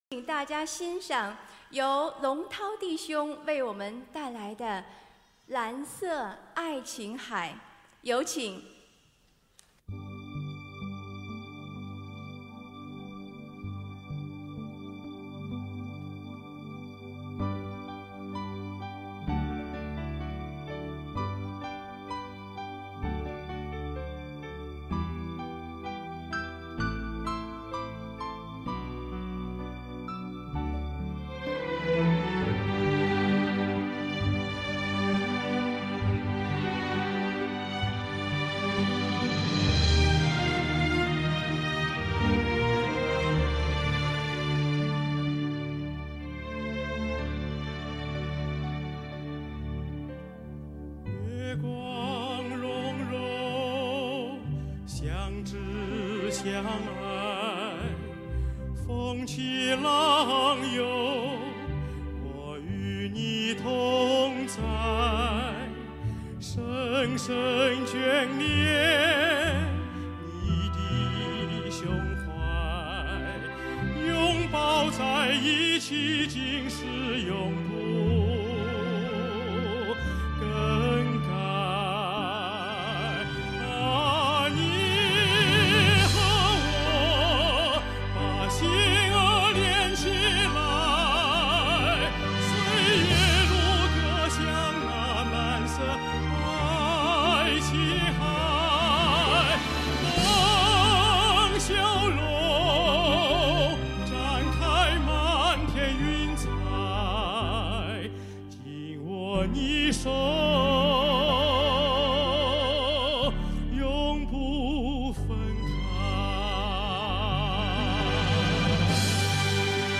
格式：MP3 数量：1 时长： 时间：2014 地点：新西兰